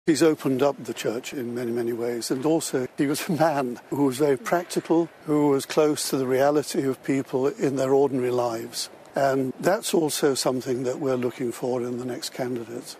Cardinal Arthur Roche is among those eligible to vote - he says they want to build on Pope Francis' legacy.